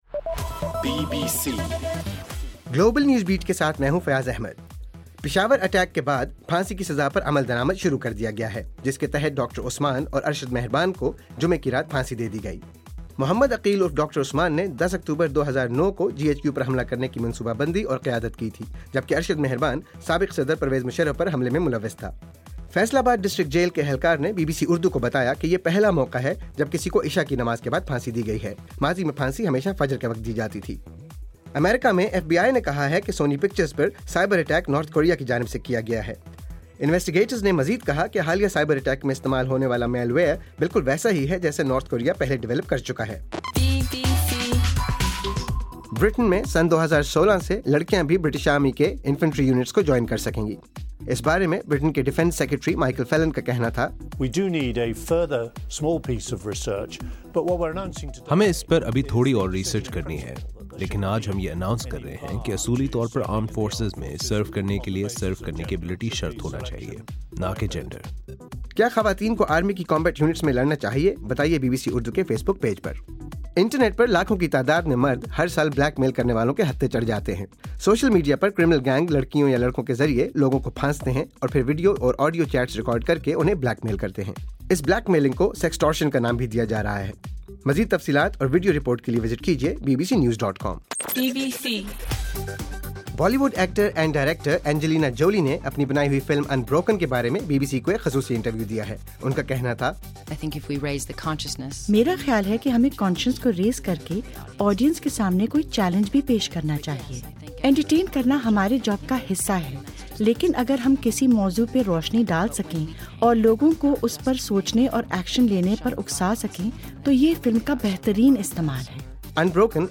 دسمبر 19: رات 12 بجے کا گلوبل نیوز بیٹ بُلیٹن